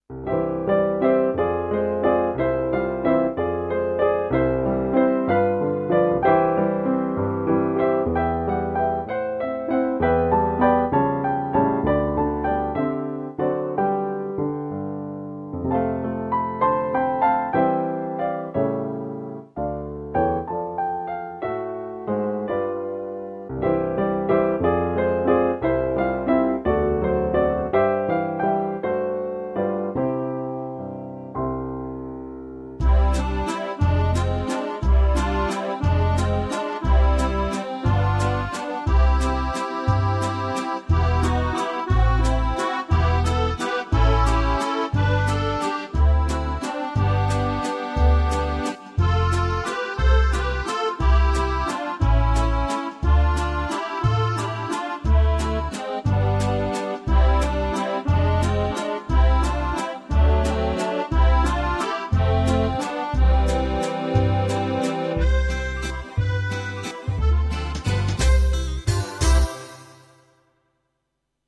This recording was made to illustrate that difference. This little waltz* is first played on a piano (a Yamaha Clavinova CLP-50 but it's a pretty good imitation of a real piano) and then using the 'French Waltz' auto-accompaniment on the Casio. Though the use of the accordion with drums and bass is interesting, the rhythm is of course fixed, so that the slight rubato (speed variations) and the expressiveness of the piano version is lost. The recording finishes with an automatic ending, without which it would rather lurch to a stop.
*The waltz is my own composition, a pastiche of German cabaret music: you can hear the complete GarageBand version here.